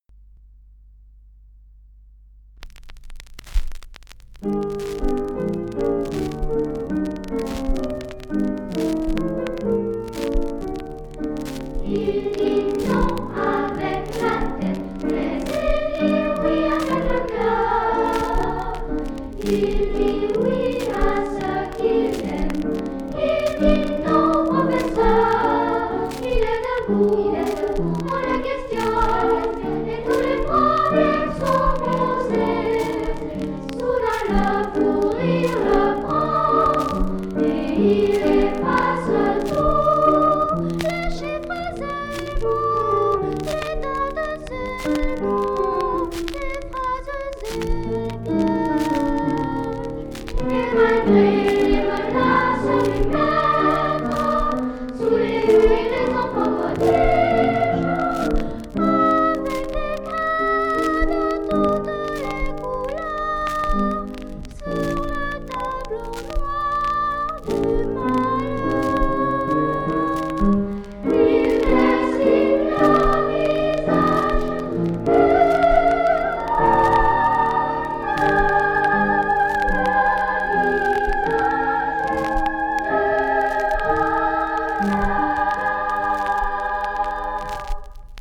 Quelques extraits des 33 tours enregistrés